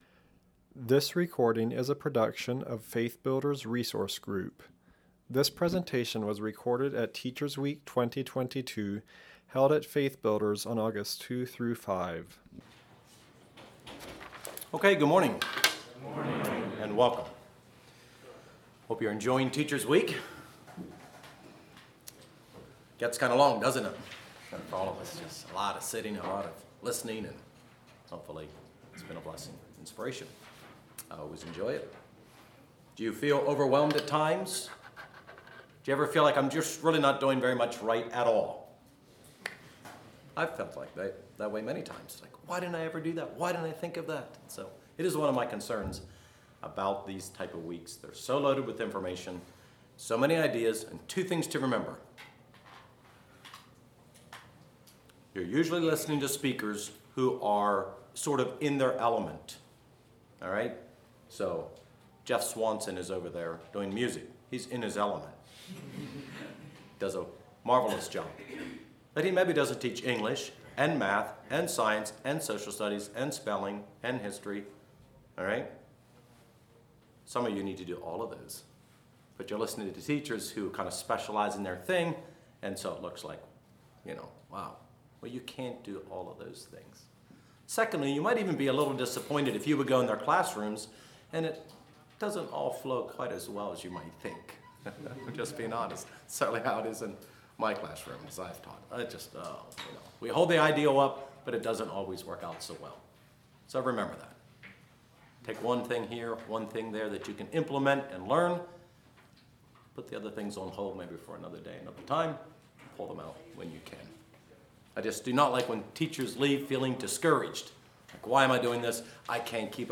Home » Lectures » Authority and Structure for a Disciplined Classroom